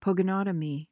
PRONUNCIATION:
(po-guh-NAH-tuh-mee)